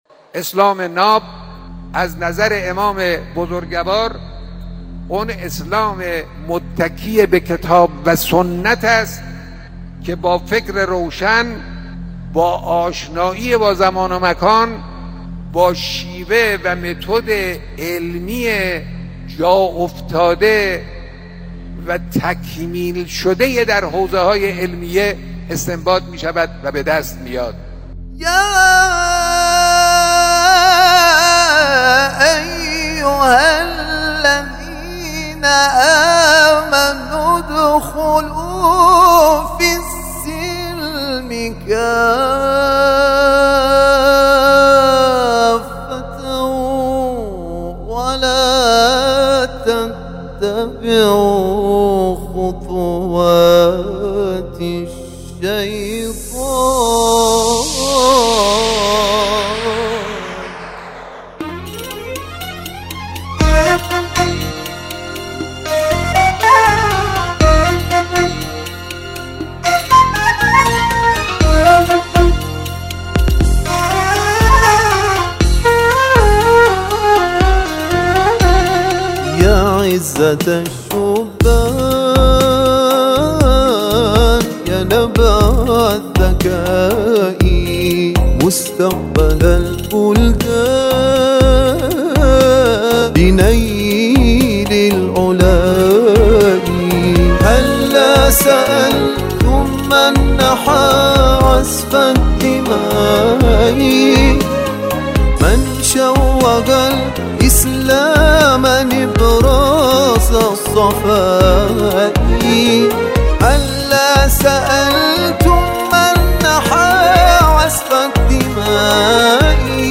برچسب ها: موسیقی ، خواندگی ، پیام